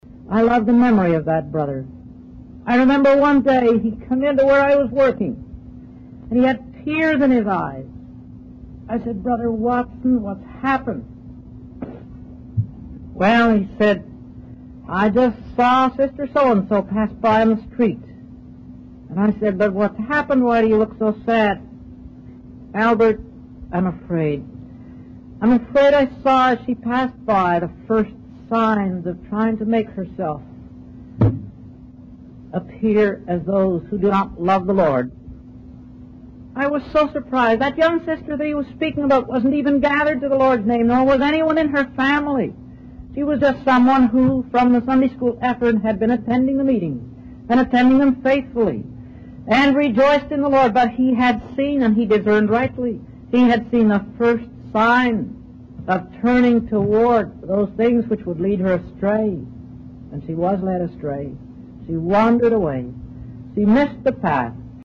They go from the 1960s to the 80s, are of varying degrees of sound quality, but are pretty much all giving exactly the same message, despite there being about sixty of them.